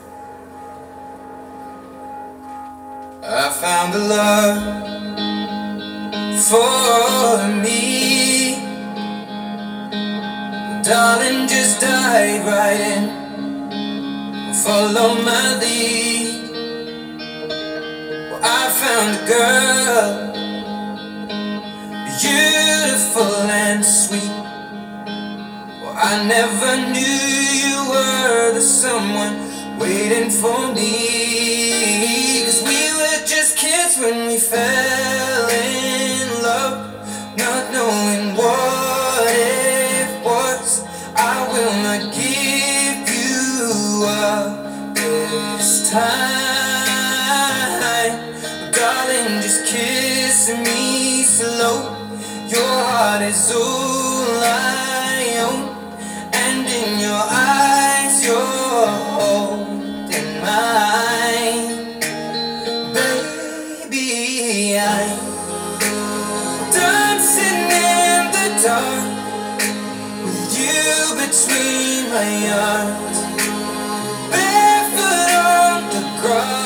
Piezo-speaker-Foam-panel-P.mp3